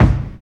45 KICK 4.wav